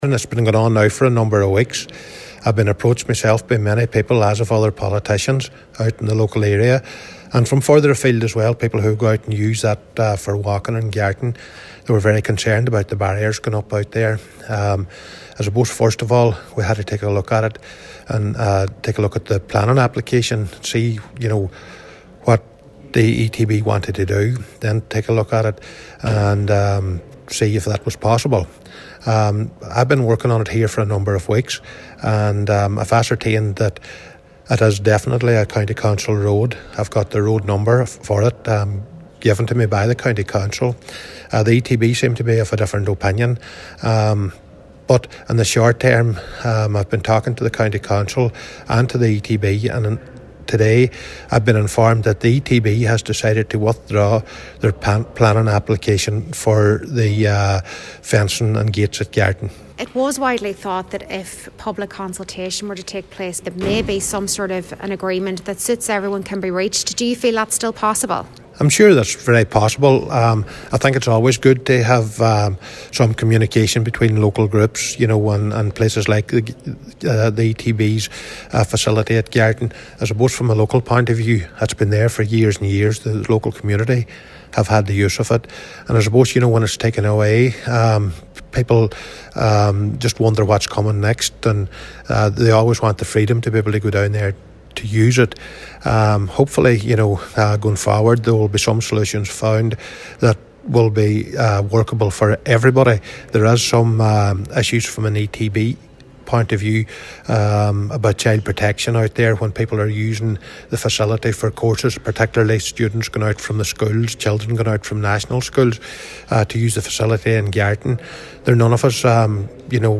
Cllr Michael McBride says the decision to reverse the application will bring relief to residents in the area.